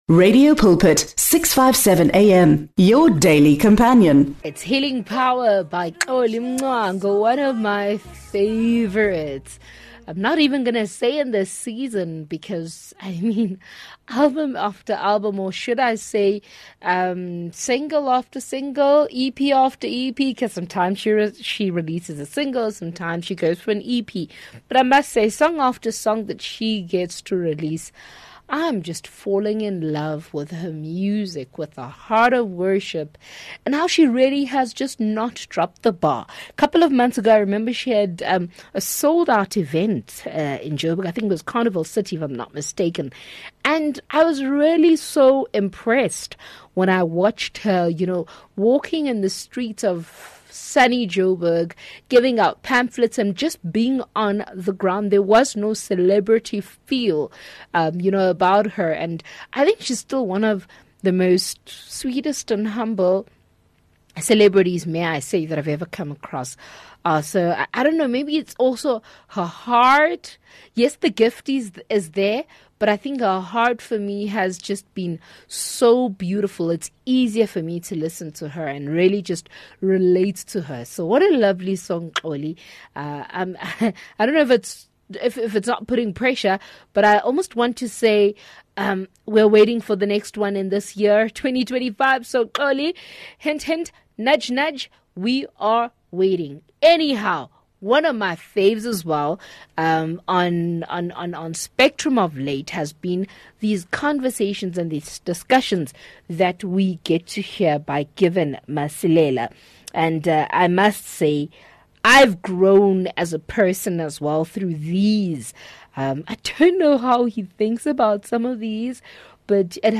in great conversation